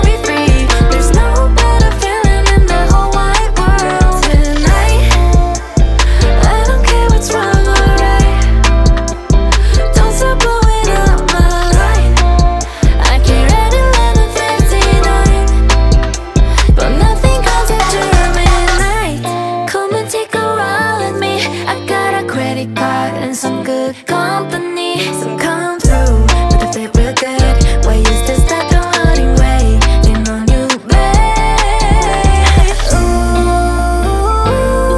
Жанр: K-pop / Поп / Русские